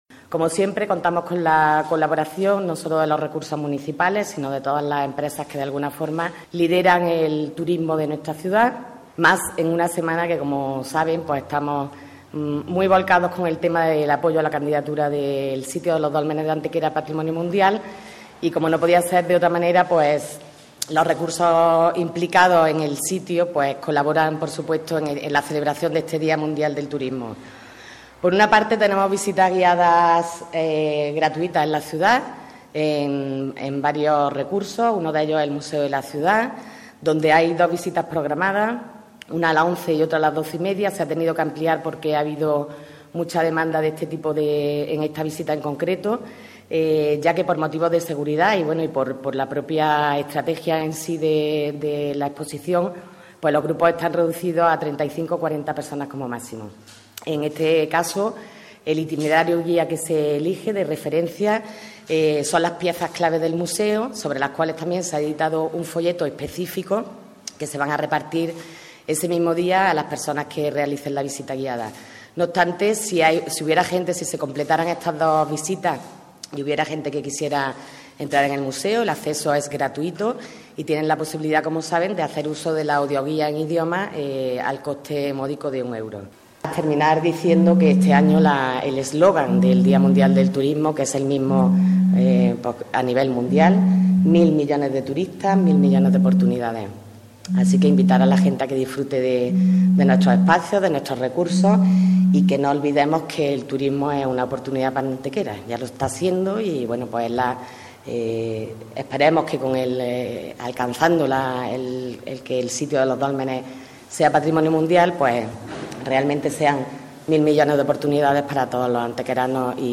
La teniente de alcalde delegada de Turismo, Comercio y Promoción para el Empleo, Belén Jiménez, ha informado en rueda de prensa del programa de actividades que el próximo fin de semana conmemorarán en Antequera el Día Mundial del Turismo, que se celebra el domingo 27.